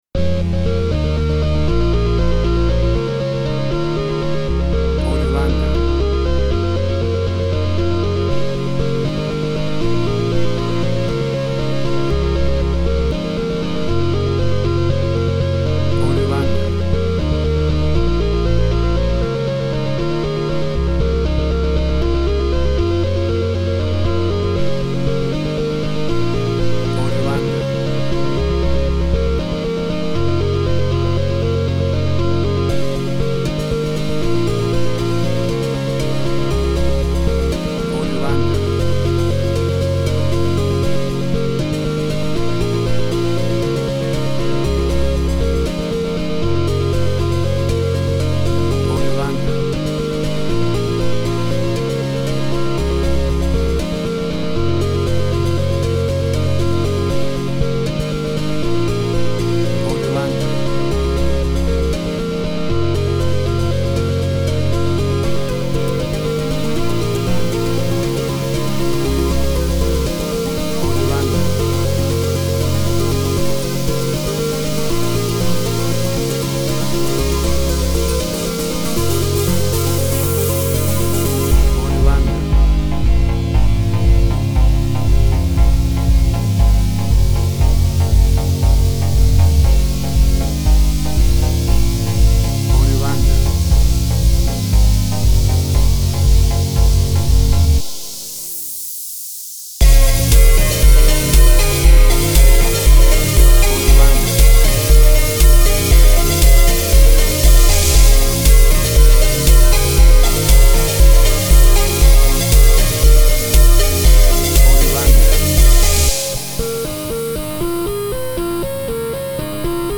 WAV Sample Rate: 16-Bit stereo, 44.1 kHz
Tempo (BPM): 120